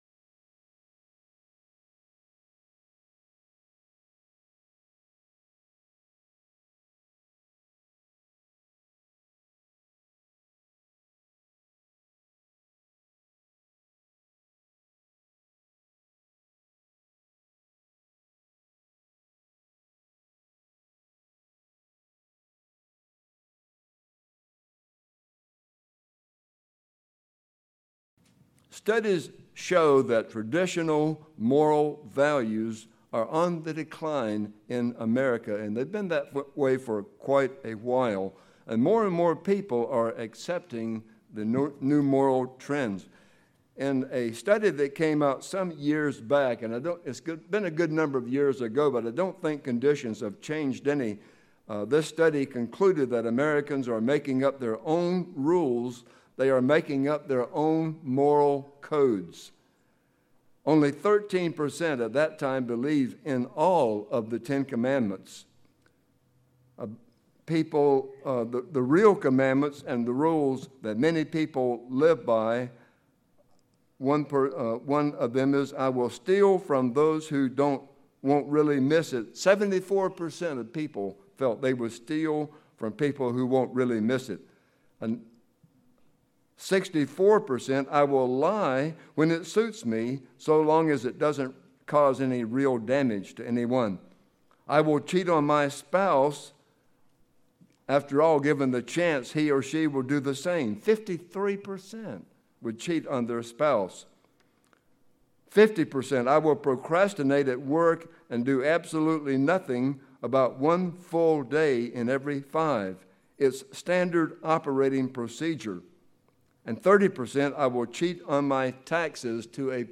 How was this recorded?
(The sermon starts at the 30 second mark.) An biblical exploration of the patriarchs and apostles who bent under pressure and those who didn't and how it should apply to us today.